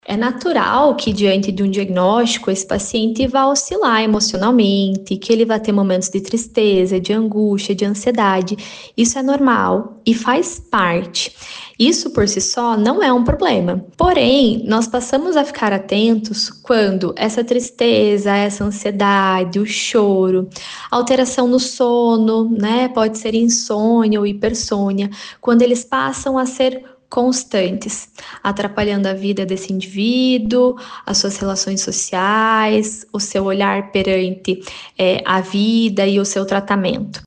Ao receber o diagnóstico, a psicóloga explica que é natural a variação emocional do paciente. Porém, é preciso ficar atento com a constância de sintomas como ansiedade, tristeza, alterações no sono ou apetite e desesperança ou apatia.